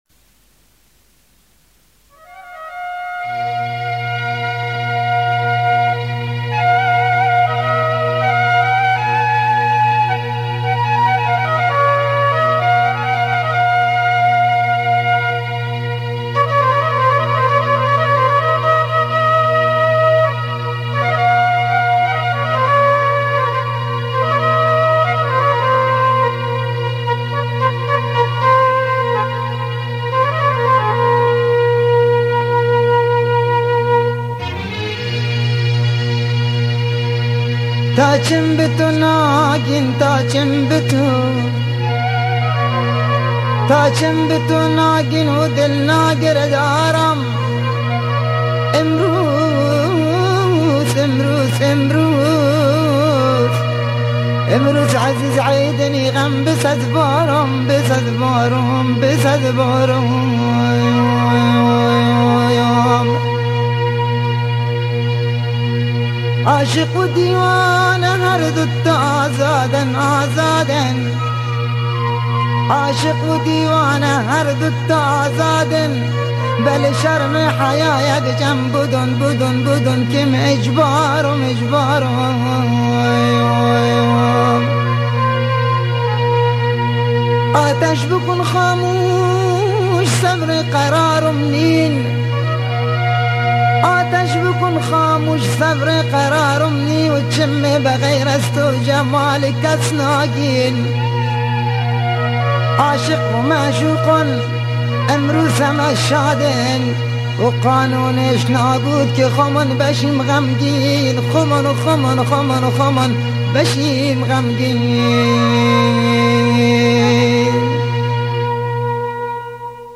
بندری